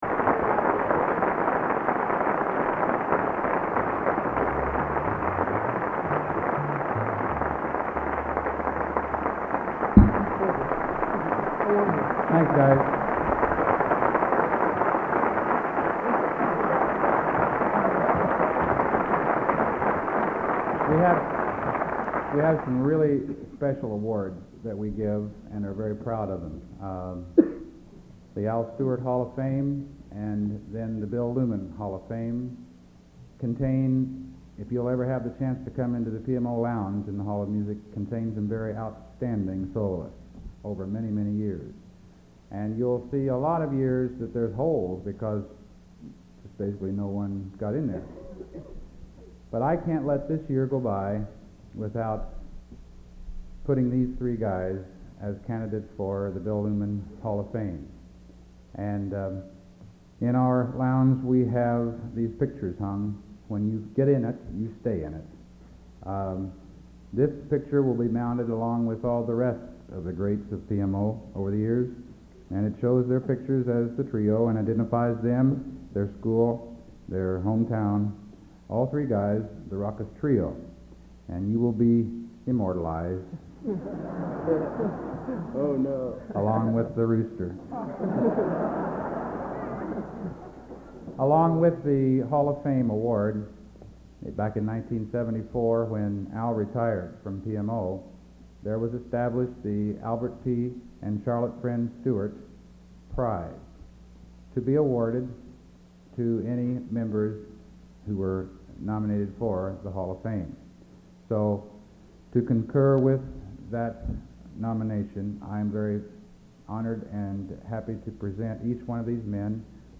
Collection: End of Season, 1983
Genre: | Type: Director intros, emceeing |Featuring Hall of Famer